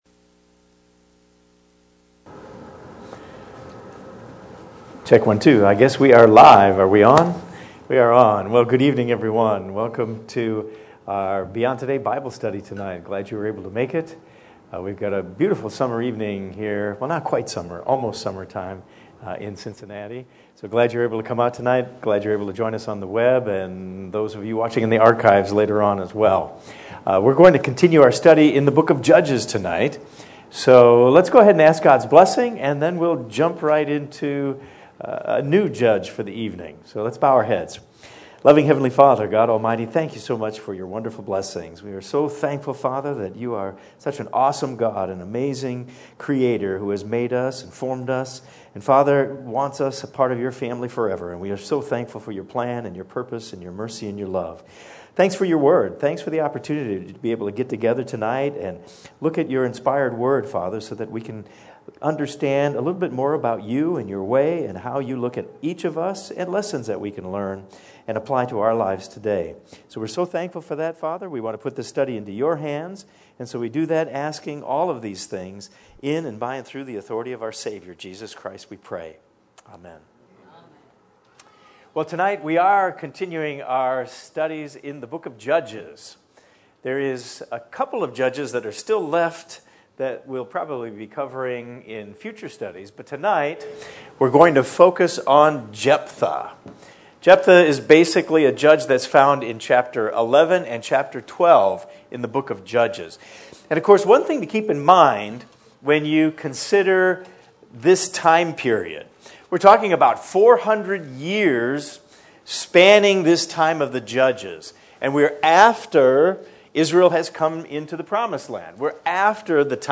This study will take a deeper look at the life of Jephthah and uncover lessons we can learn for today.